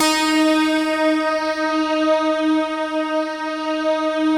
SI1 PLUCK07R.wav